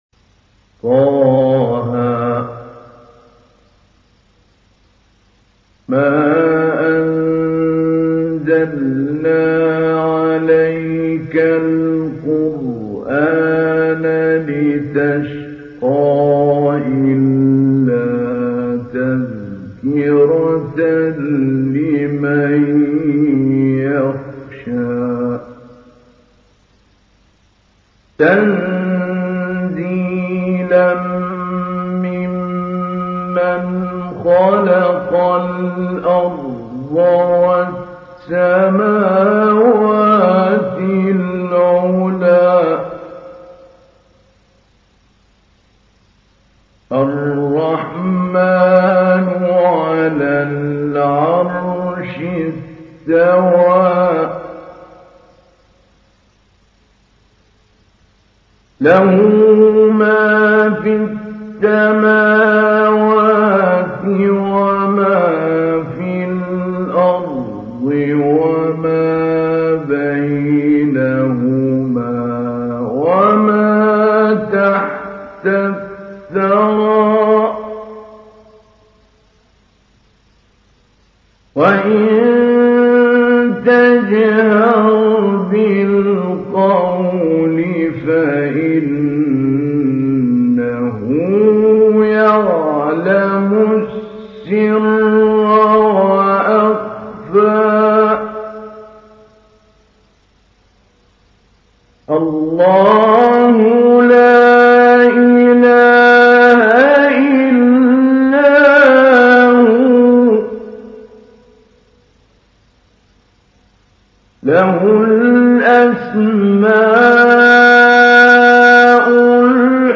تحميل : 20. سورة طه / القارئ محمود علي البنا / القرآن الكريم / موقع يا حسين